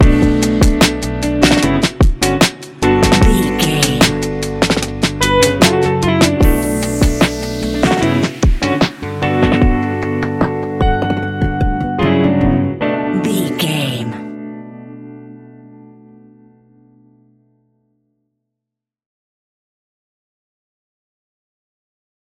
Ionian/Major
D♭
chilled
laid back
Lounge
sparse
new age
chilled electronica
ambient
atmospheric
instrumentals